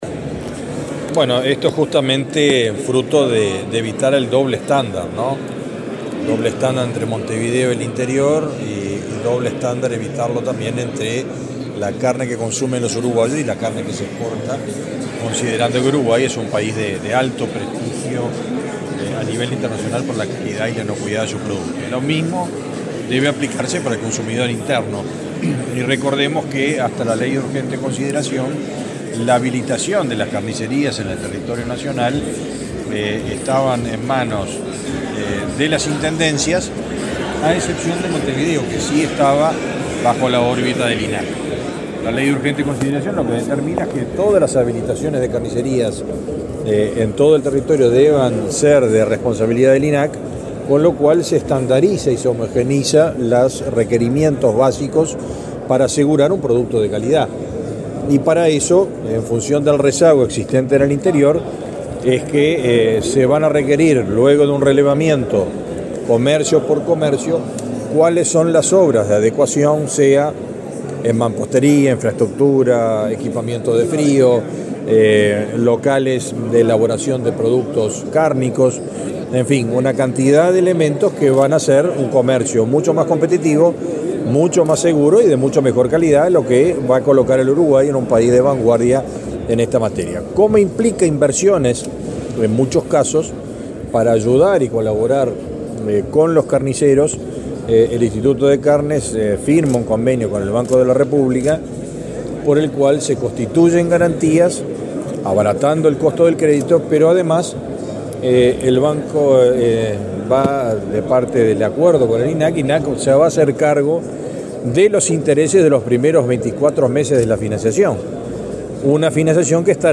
El ministro de Ganadería, Fernando Mattos, dialogó con la prensa, luego de participar en la firma de un convenio entre el Instituto Nacional de Carnes